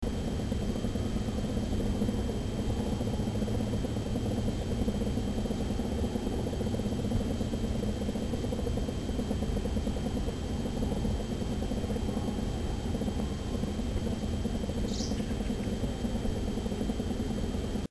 I’ve got my ear tuned to something rare – a unique, collective buzz, loud enough to reach 100 feet on a good day.
A backyard hive with a plexiglass inner cover became the stage for the encore.
Here’s a glimpse into the night session:
Night Sound. It’s the hive’s own lullaby.